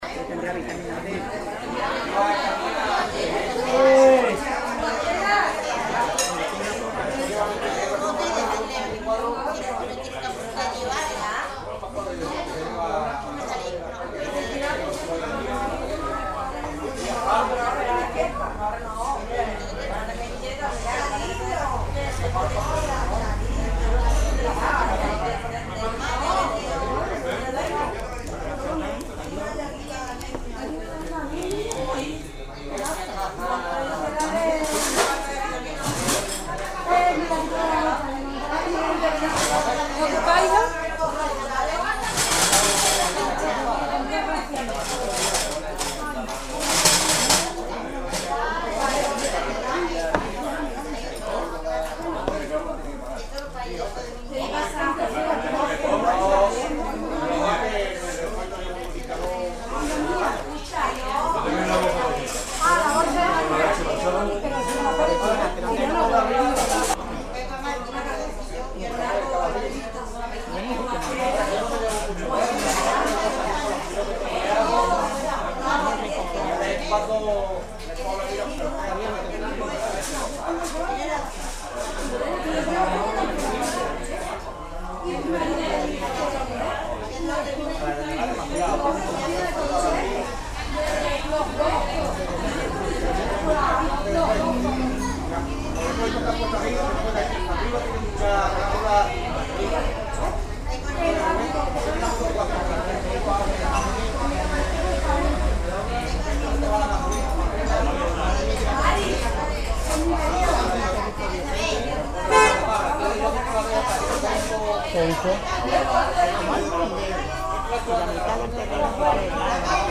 FoundryVTT/src/modules/soundfxlibrary/Tavern/Loops/Chatter/chatter-2.mp3 at f054a31b20ef2bee578a579ba110bb7db075db9c
chatter-2.mp3